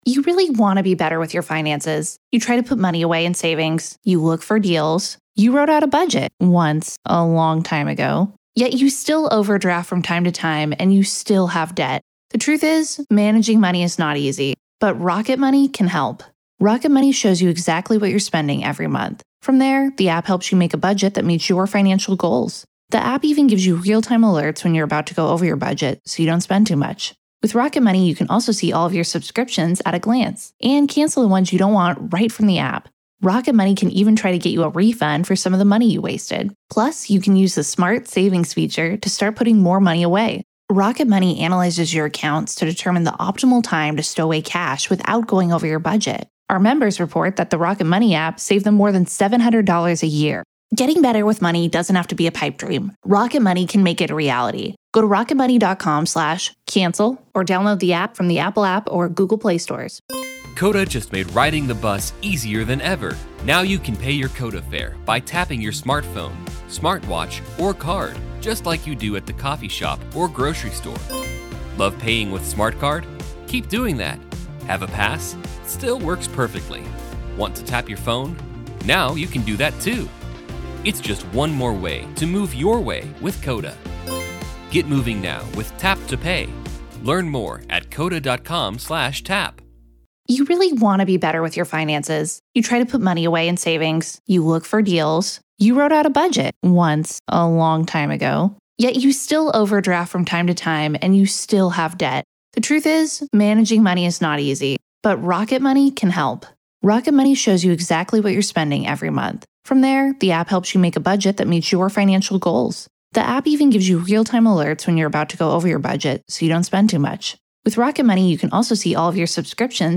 a caller from California shares an experience that still doesn’t sit right years later. It involves a quiet house in the middle of the night, a child doing something she had never done before, and a voice that knew exactly what it was saying—and how to say it.